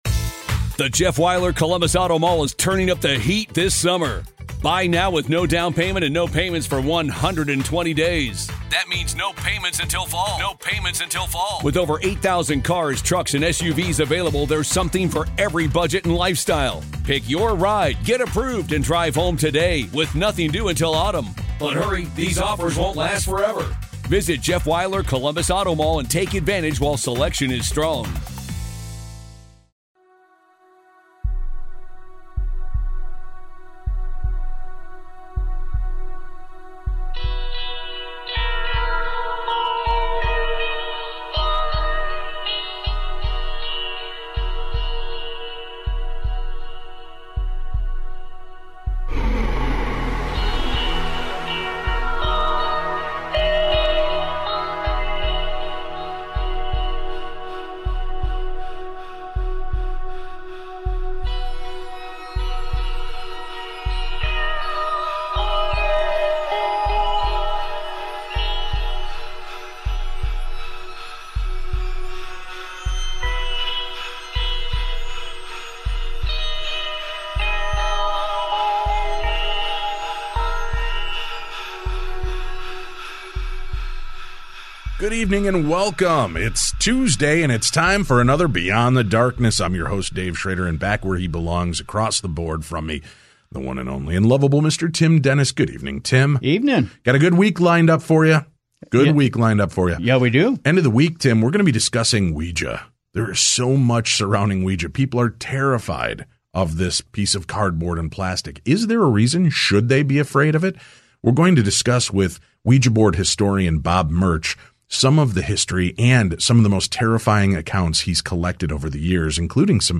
Beyond The Darkness talks to Filmmaker/Documentarian